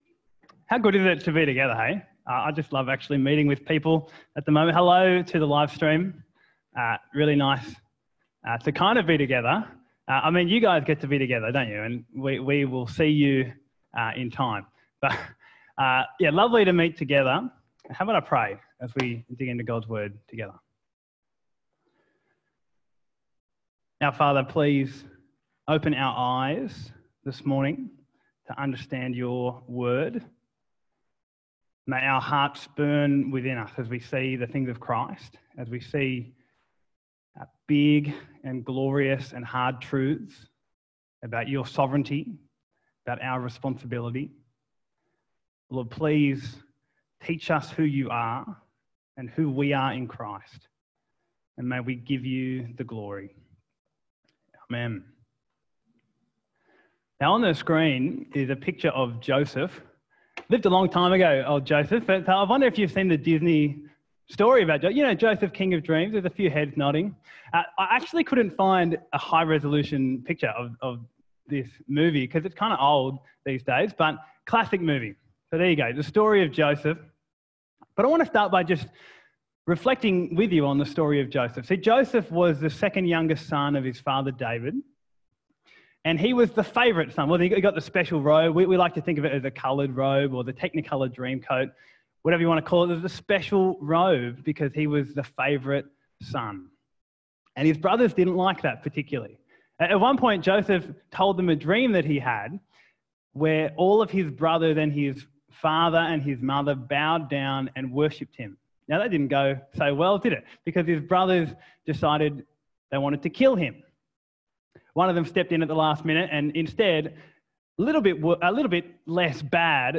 Talk Type: Wintercon